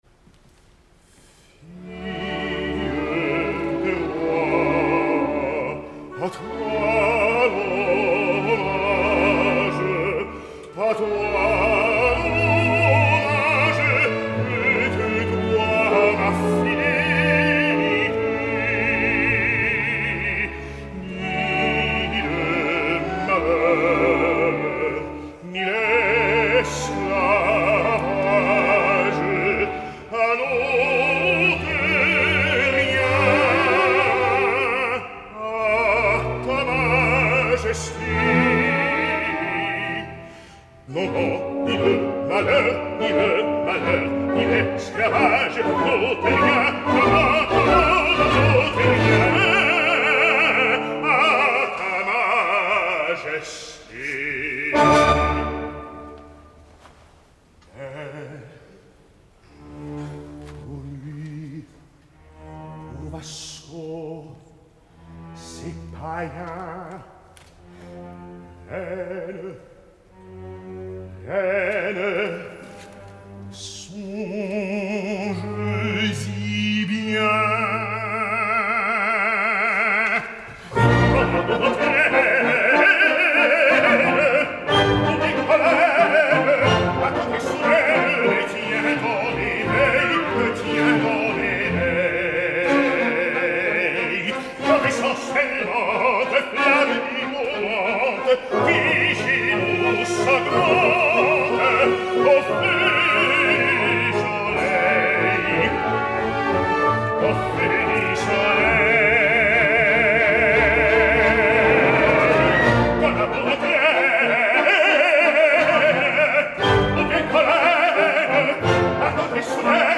Potser el cant no és intrínsecament francès, però la veu és bella, està sana i ell canta molt bé. Comproveu-ho vosaltres mateixos escoltant “Fille des rois, a toi l’hommage
Chor und Orchester der Deutschen Oper Berlin
Deutsche Oper Berlin, 15 d’octubre de 2015